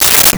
Space Gun 07
Space Gun 07.wav